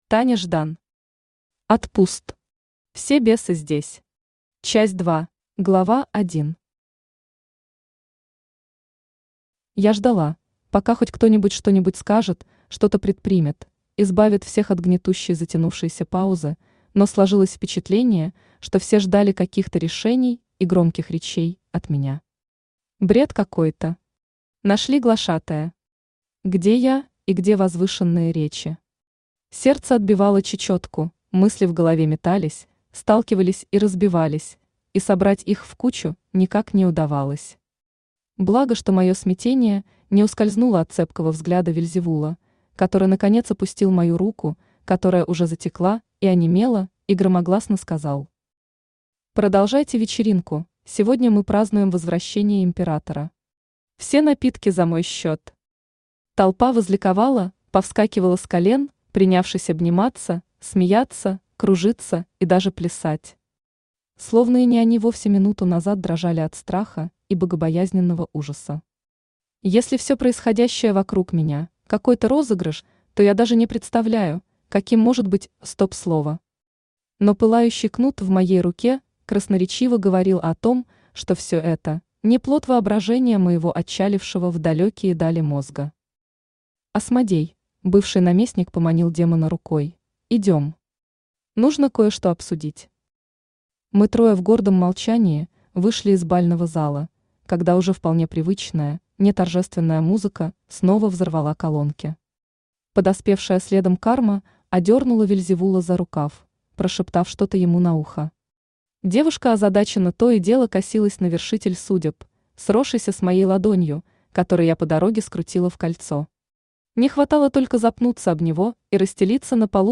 Аудиокнига Ад пуст. Все бесы здесь. Часть 2 | Библиотека аудиокниг